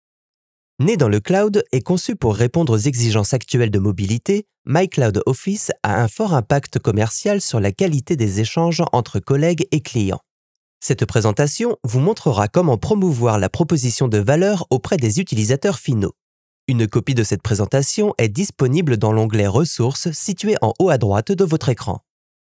Male
French (European)
Adult (30-50)
Warm, clear and fresh.
E-Learning
All our voice actors have professional broadcast quality recording studios.